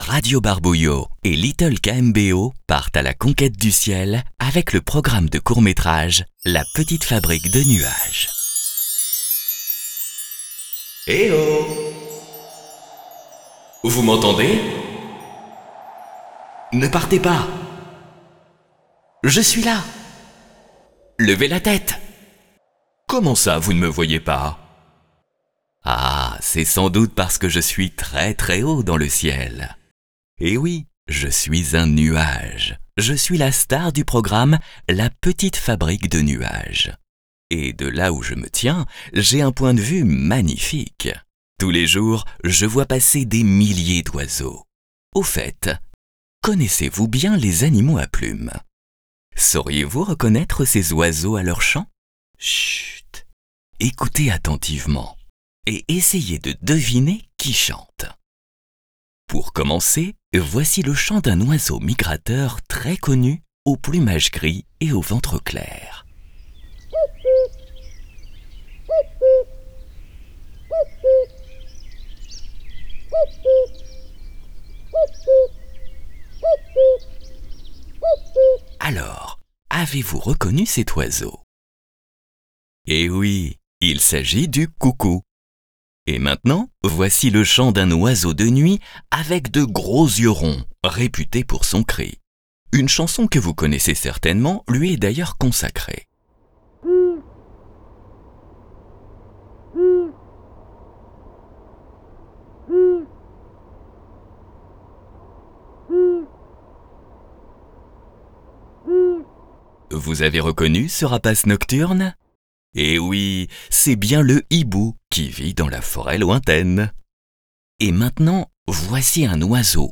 Leveil-sonore_Decouvrir-le-chant-des-oiseaux_Radio-Barbouillots-la-LPO.mp3